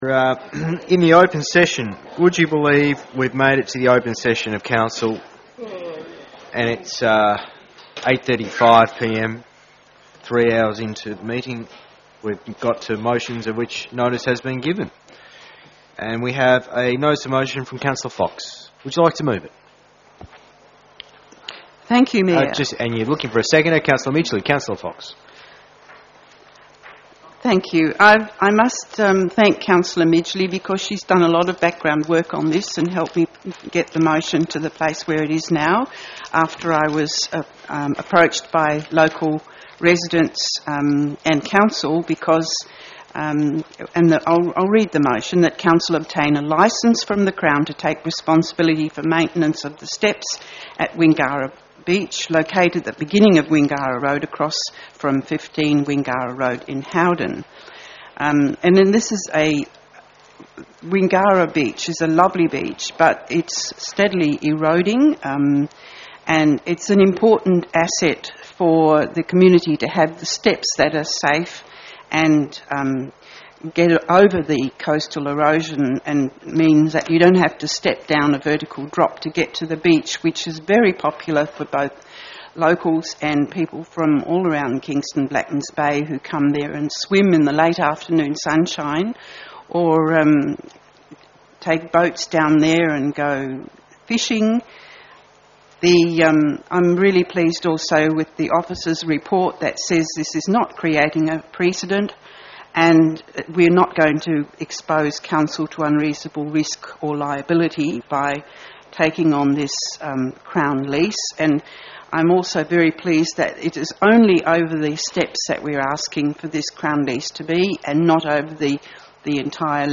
Audio Record, Council Meeting held on 3 May 2021 |